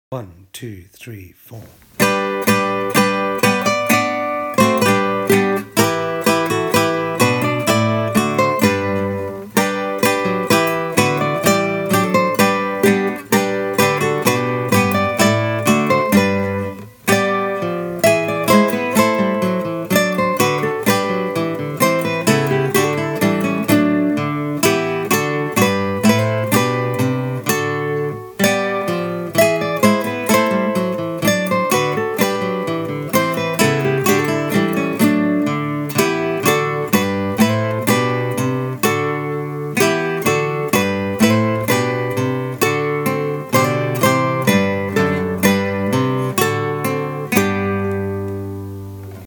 Guitar Arrangement